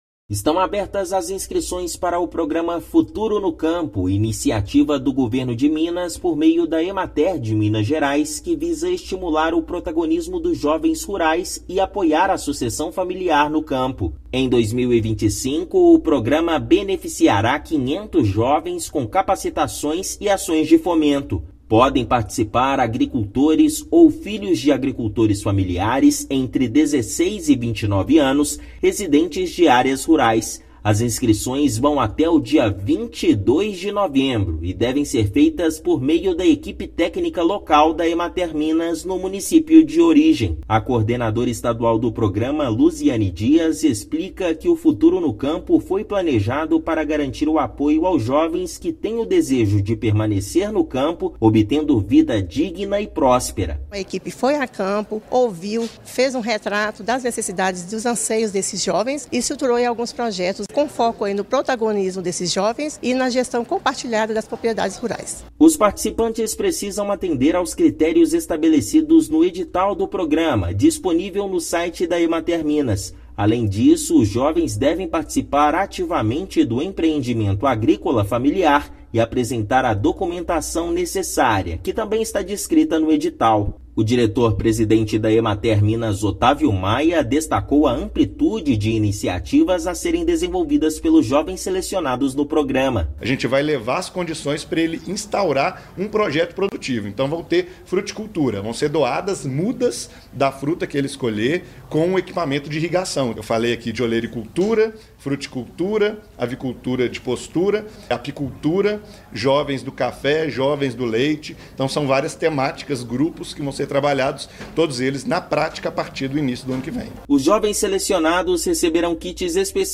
Iniciativa do Governo de Minas oferece capacitações e ações de estímulo ao desenvolvimento profissional, por meio da Empresa de Assistência Técnica e Extensão Rural de Minas Gerais (Emater-MG). Ouça matéria de rádio.